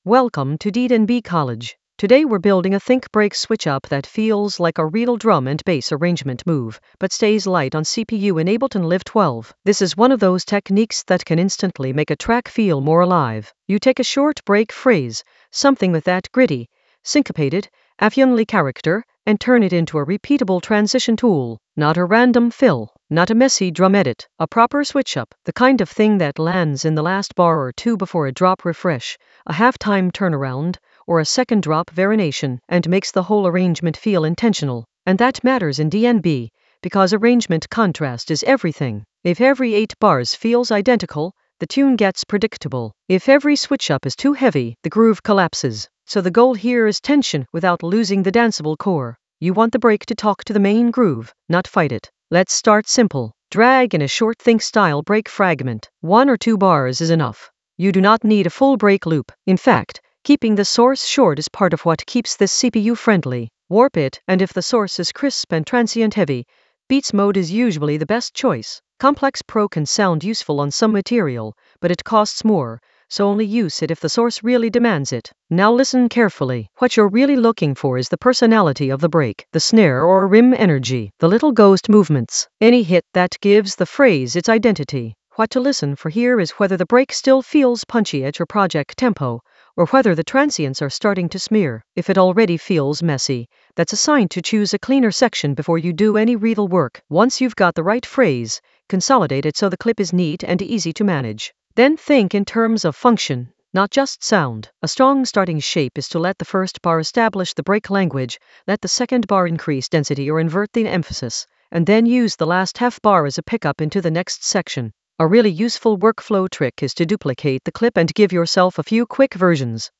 An AI-generated intermediate Ableton lesson focused on Stack a think-break switchup with minimal CPU load in Ableton Live 12 in the Sound Design area of drum and bass production.
Narrated lesson audio
The voice track includes the tutorial plus extra teacher commentary.